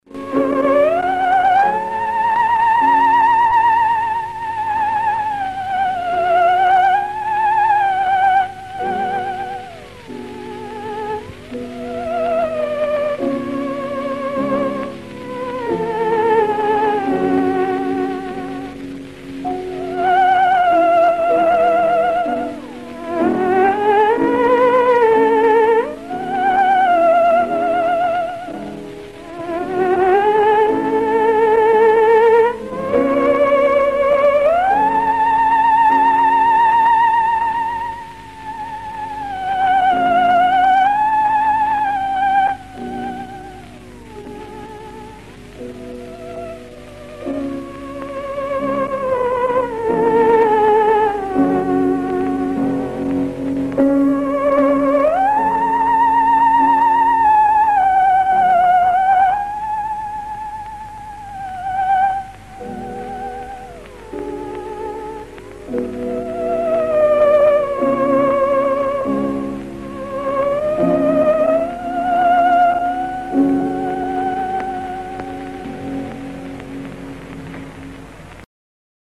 Скачал романс на инструменте терменвокс, хотелось бы такой же но с голосом.